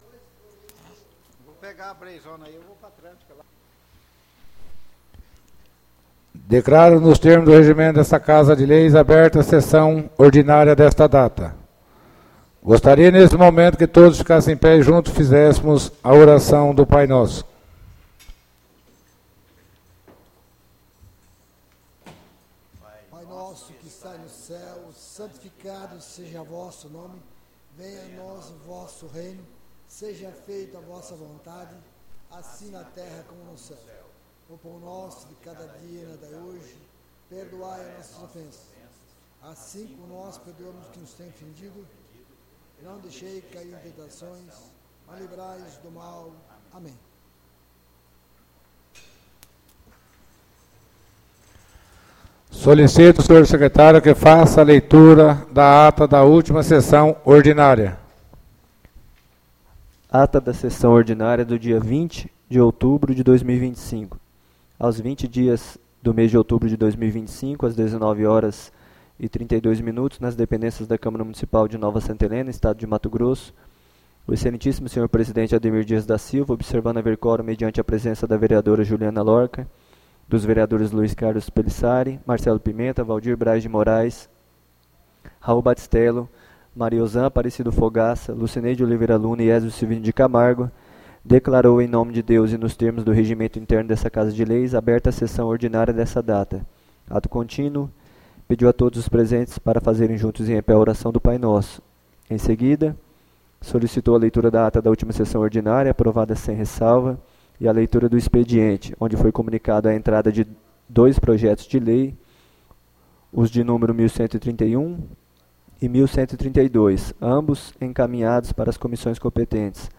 ÁUDIO SESSÃO 03-11-25 — CÂMARA MUNICIPAL DE NOVA SANTA HELENA - MT